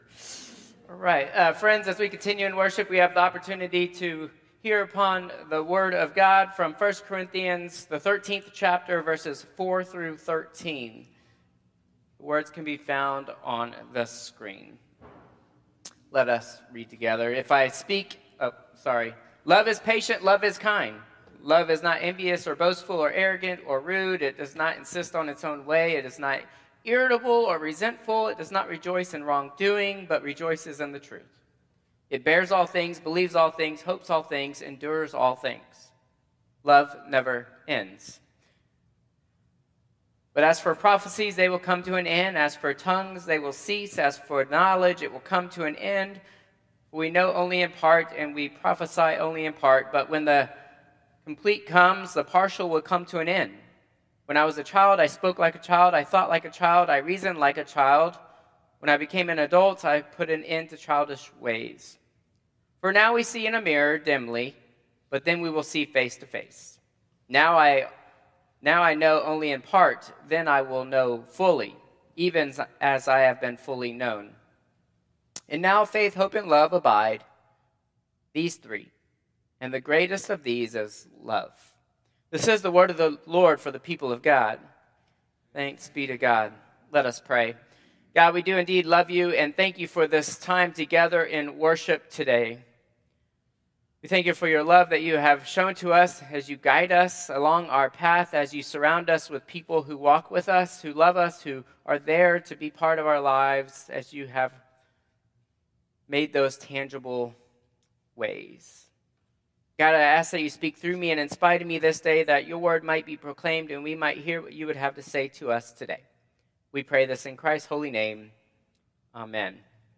Traditional Service 5/11/2025